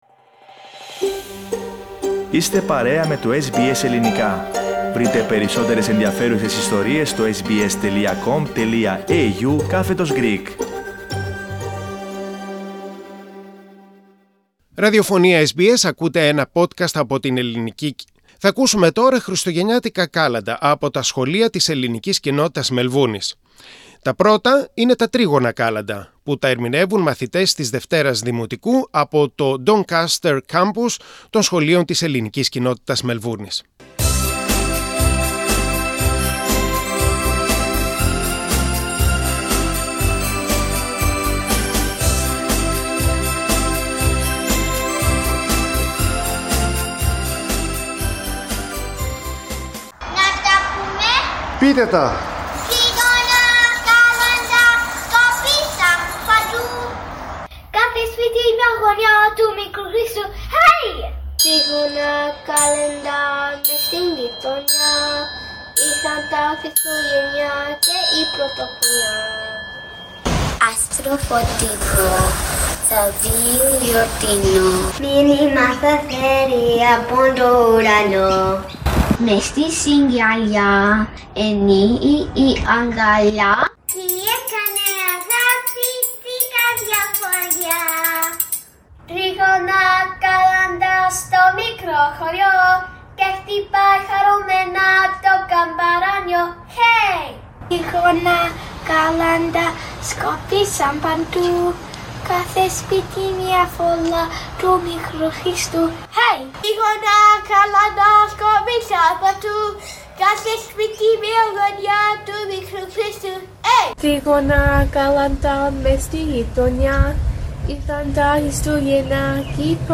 Kάλαντα από μαθητές και μαθήτριες Δημοτικού των Σχολείων της Ελληνικής Κοινότητας Μελβούρνης και Βικτωρίας.
Christmas Carols from the Greek Community of Melbourne's school students.